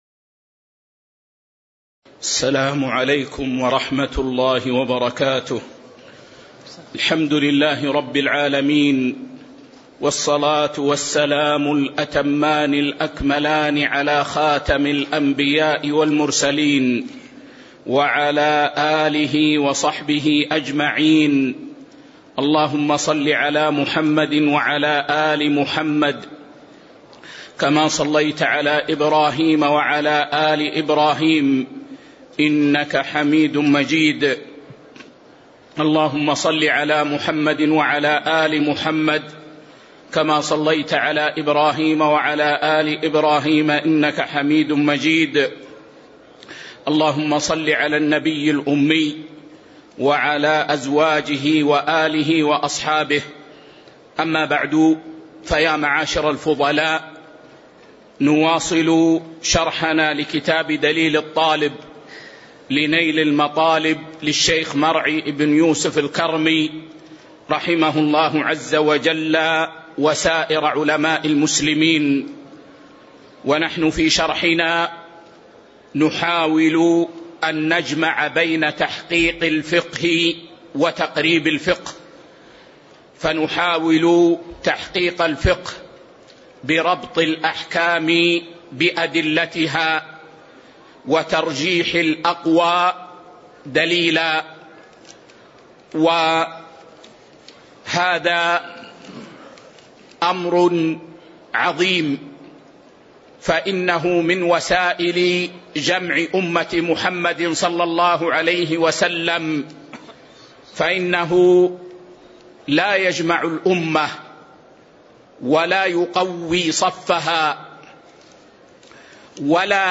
تاريخ النشر ٢٢ شوال ١٤٤٤ هـ المكان: المسجد النبوي الشيخ